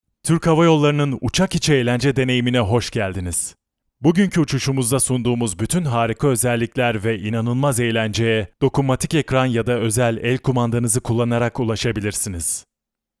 Commercial, Playful, Versatile, Warm, Corporate
Explainer